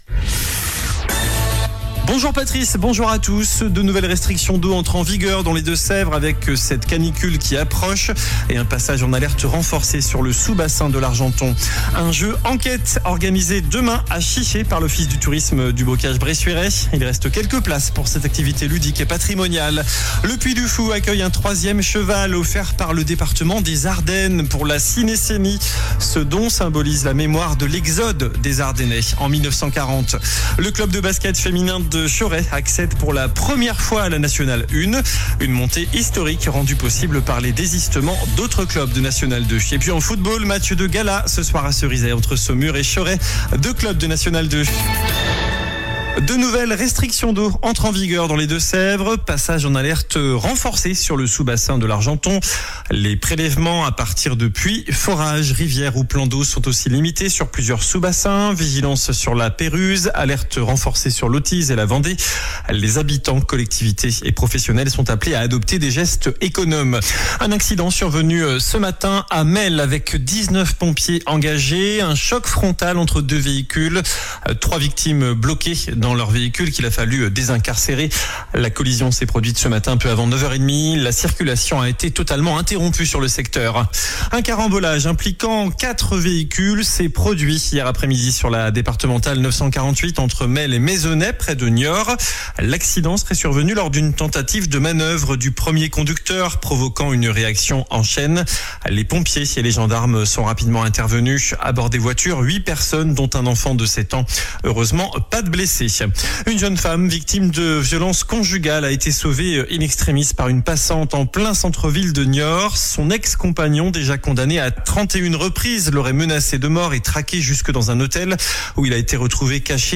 JOURNAL DU MERCREDI 06 AOÛT ( MIDI )